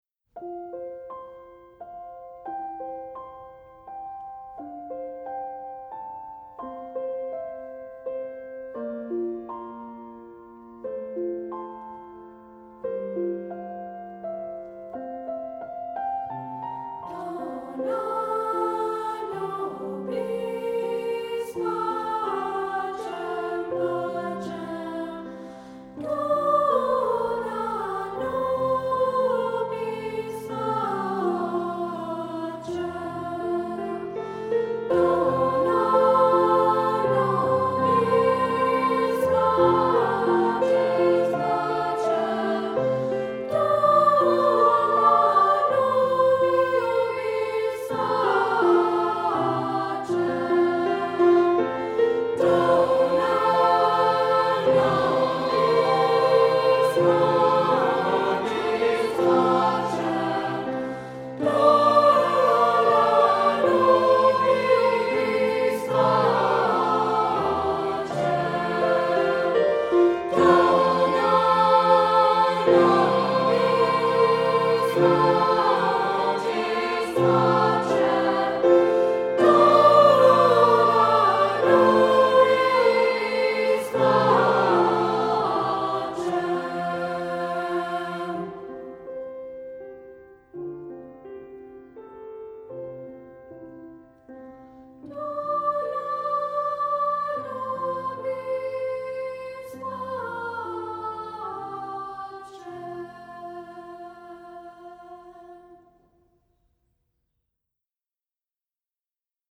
Accompaniment:      Piano